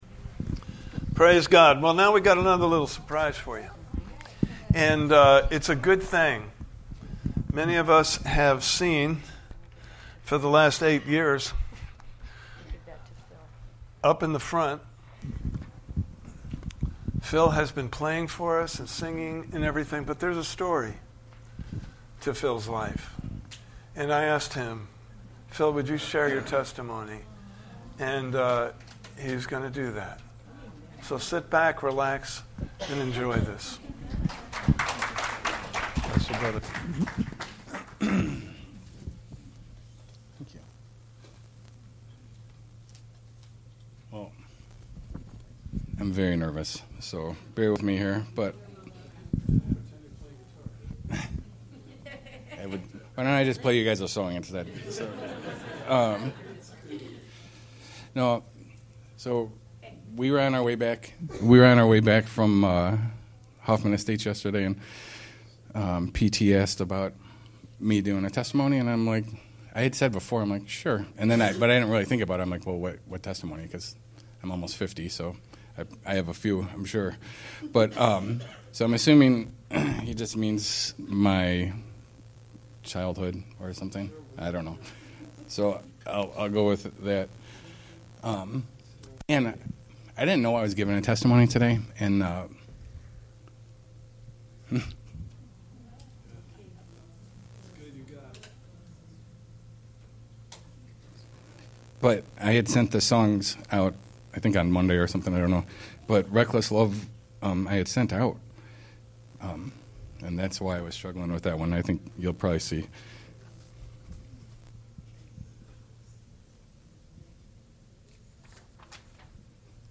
Guest Speaker / Testimony
Sunday Morning Service